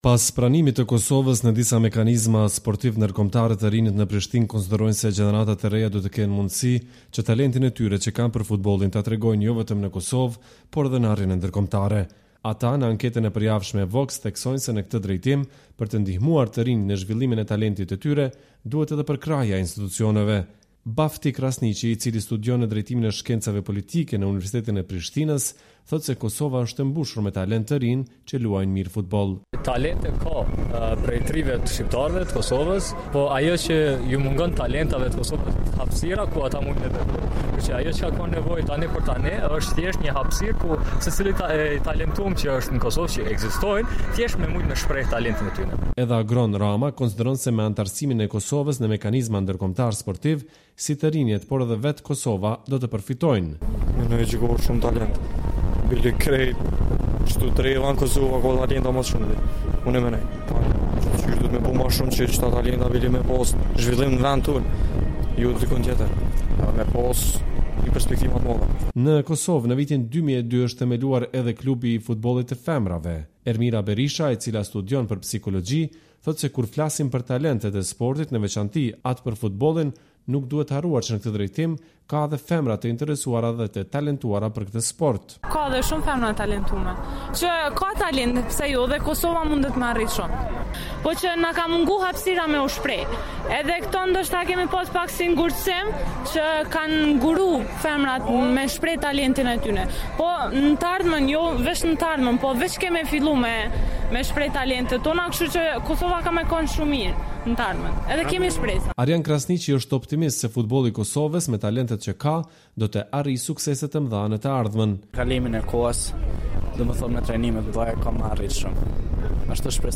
Vox me të rinj